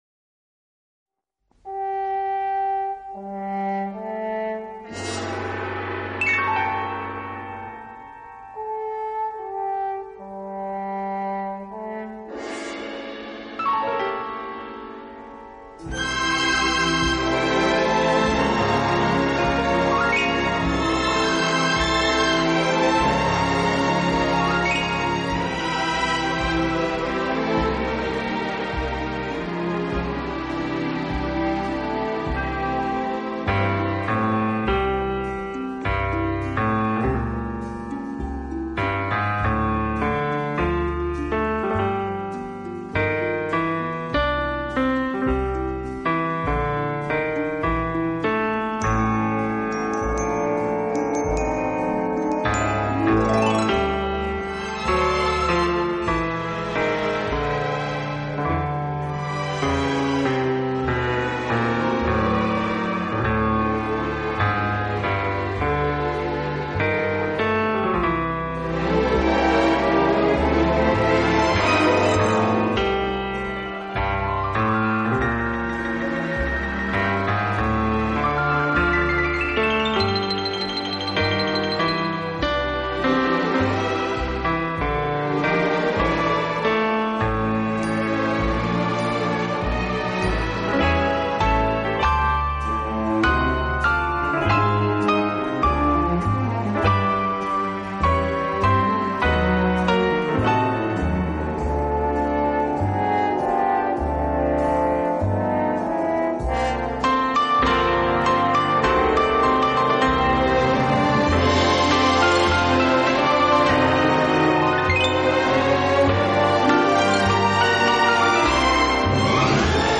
双钢琴